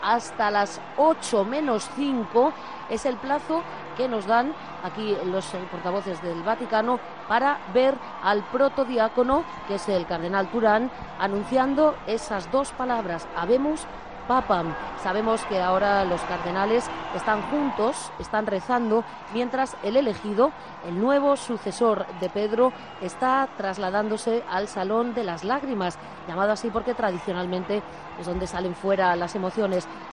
Un nuevo Papa había sido escogido. 19:06 de la tarde y comenzaron a sonar las campanas en la Plaza San Pedro.